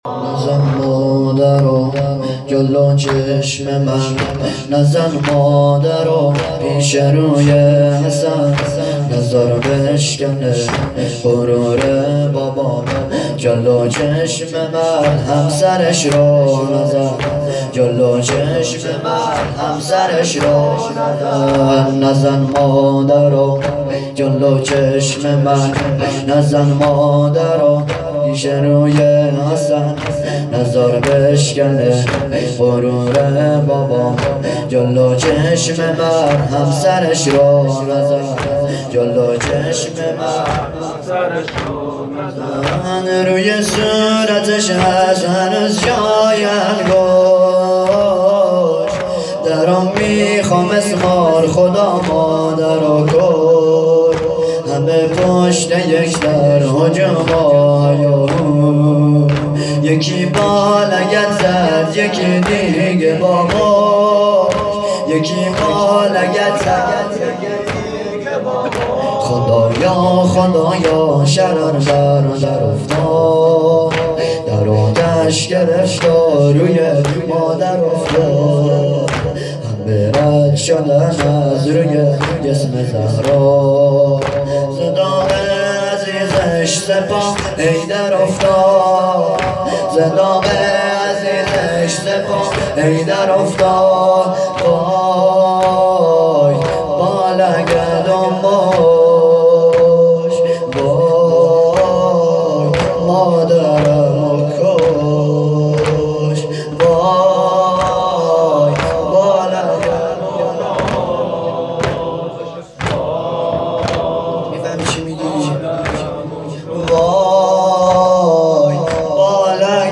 توضیحات: هیئت صادقیون حوزه علمیه زابل